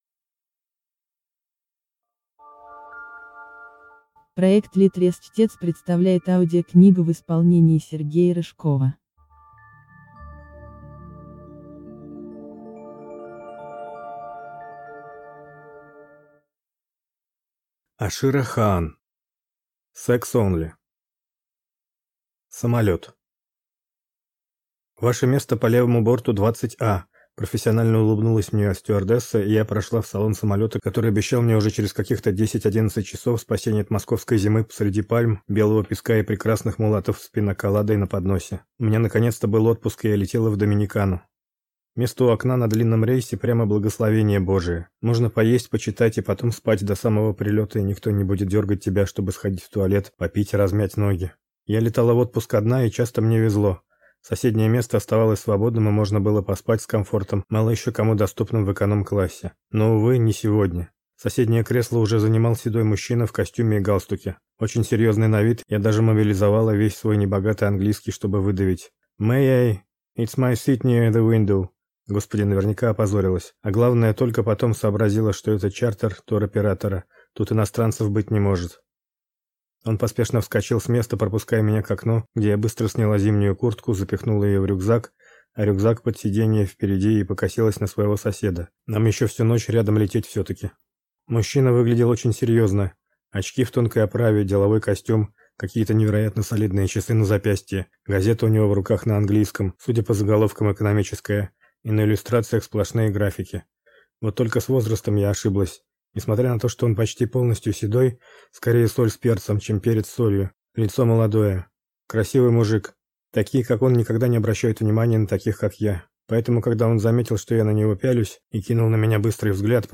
Аудиокнига Sex Only | Библиотека аудиокниг